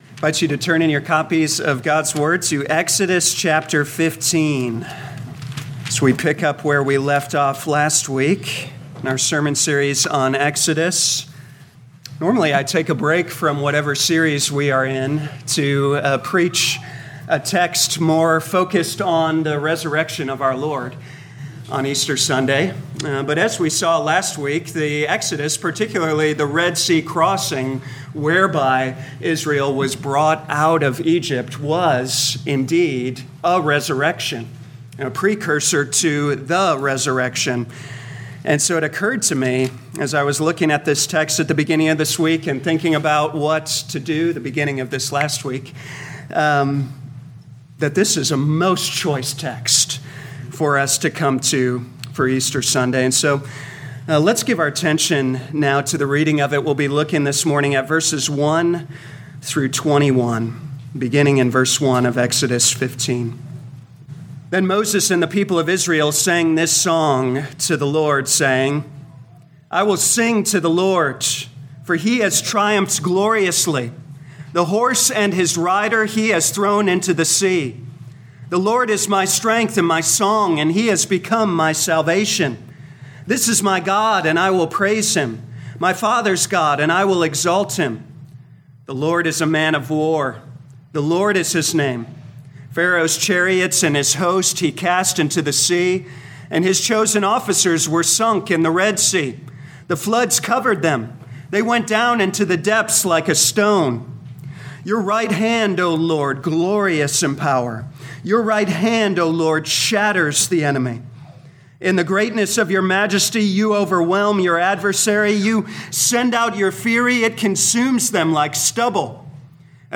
Audio Notes Bulletin All sermons are copyright by this church or the speaker indicated.